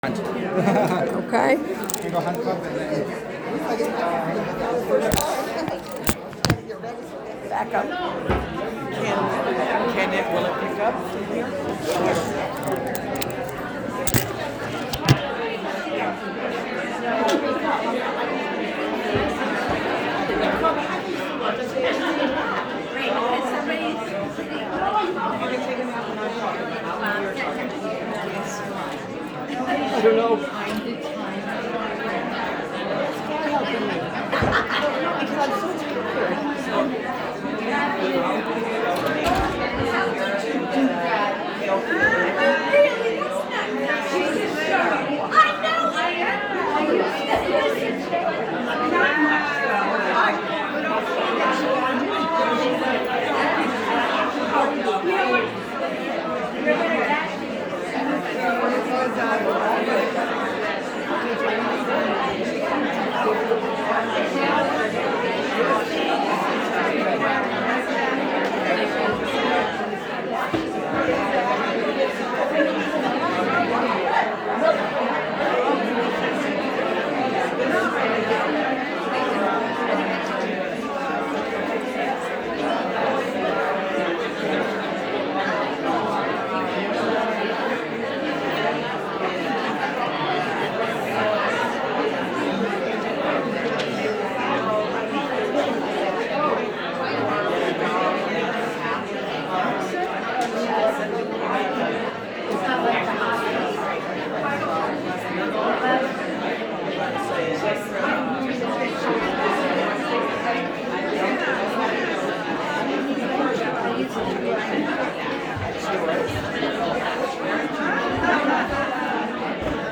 A workshop given at the 2024 OA Region 6 convention, held in October in Nashua, NH, US.